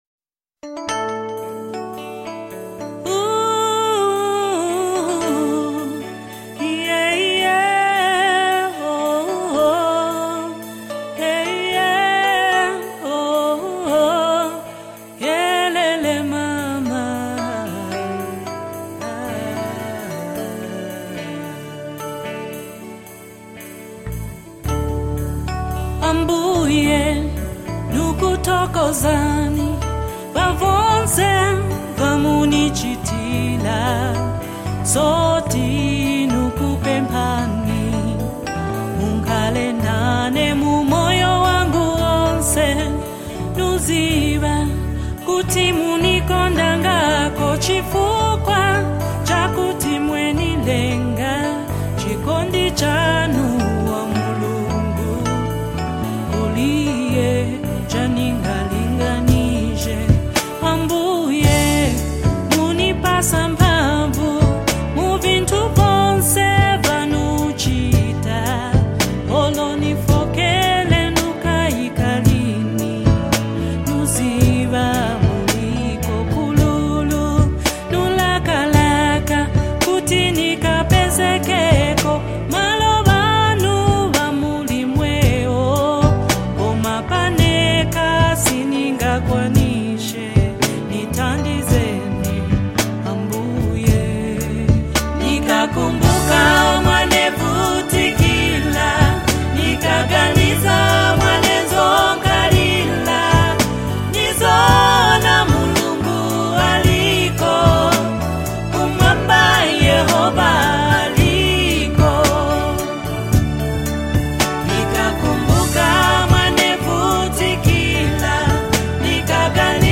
spiritually uplifting track